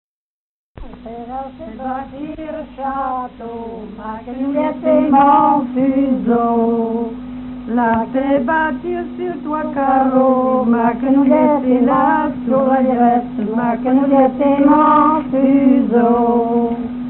circonstance : fiançaille, noce ;
Genre laisse
Pièce musicale inédite